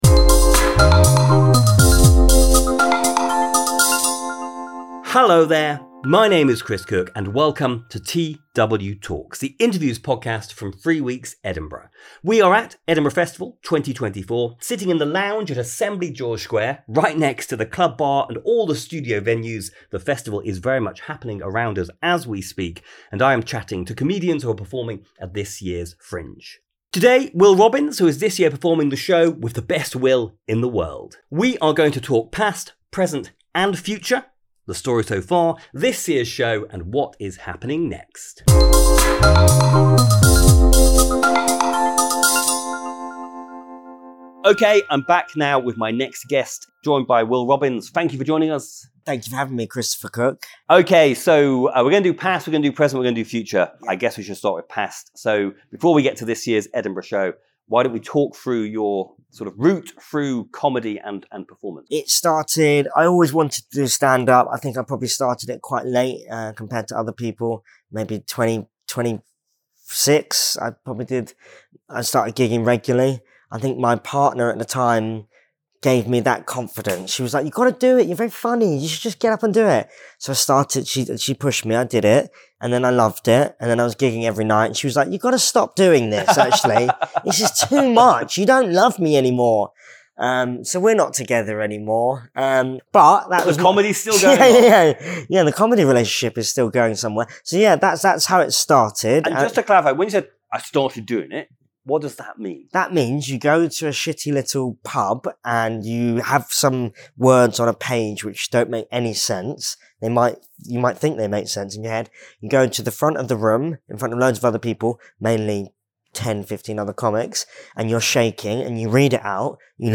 TW:Talks is an interviews podcast
talks to some of our favourite comedians and performers. We’ve published two series to date from Edinburgh Fringe 2023 and 2024.